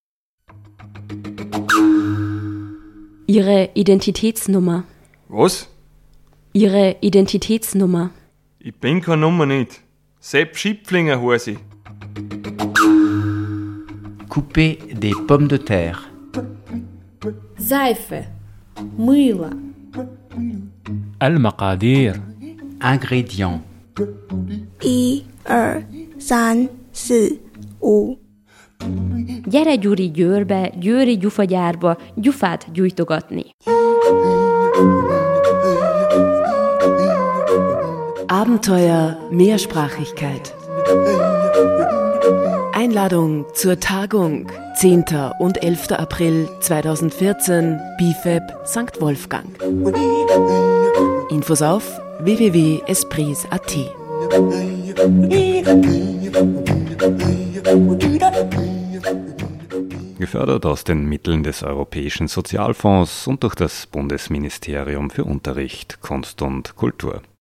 ESPRIS-jingle-einladung-tagung.mp3